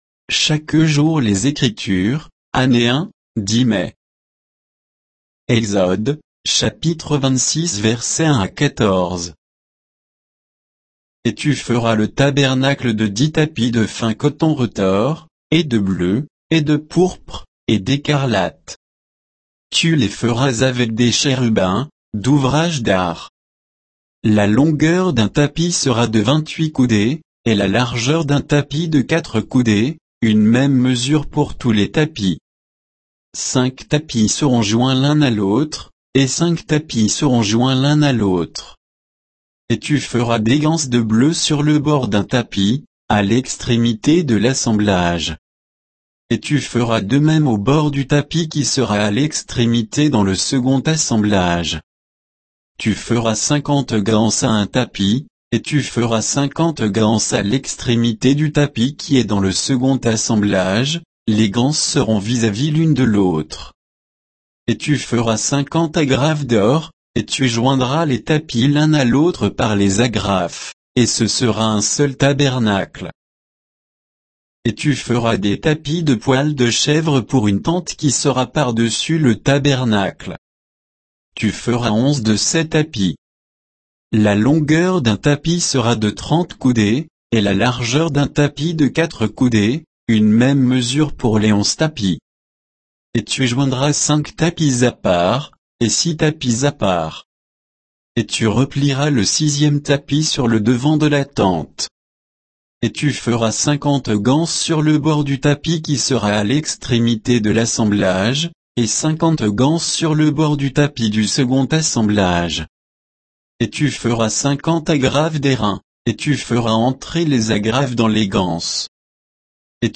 Méditation quoditienne de Chaque jour les Écritures sur Exode 26, 1 à 14